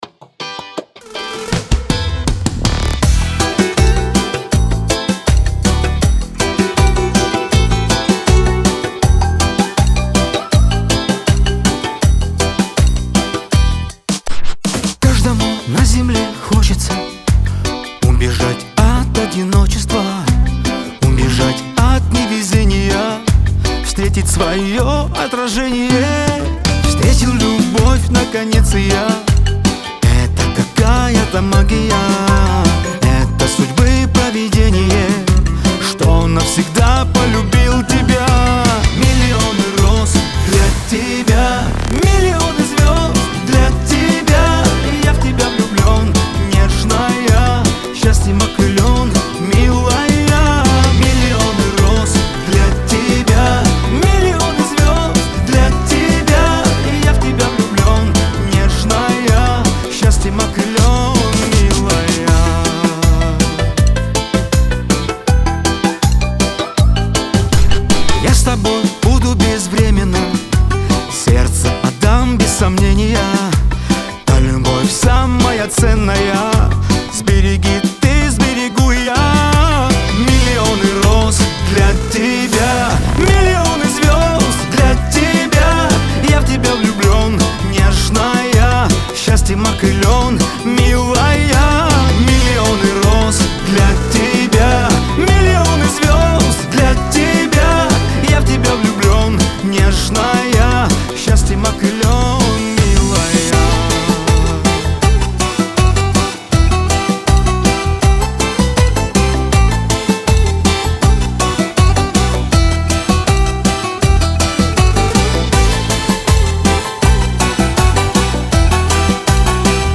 Кавказ – поп , Лирика
грусть